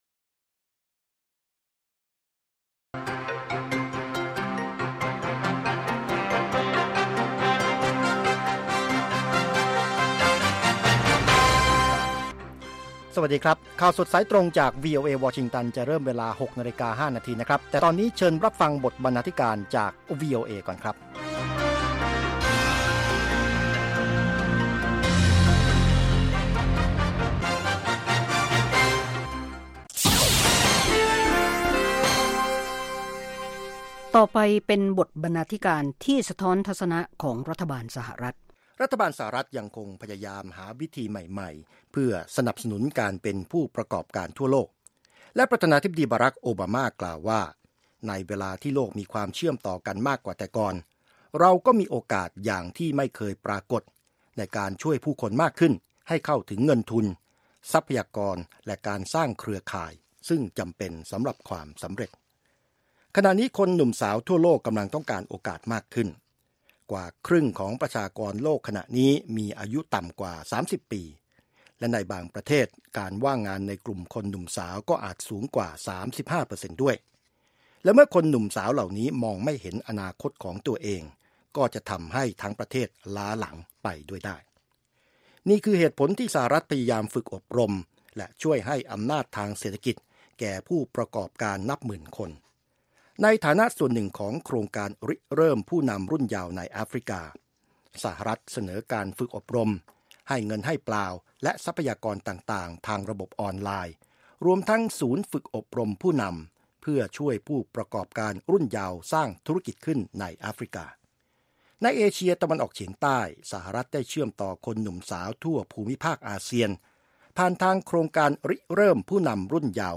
ข่าวสดสายตรงจากวีโอเอ ภาคภาษาไทย 6:00 – 6:30 น.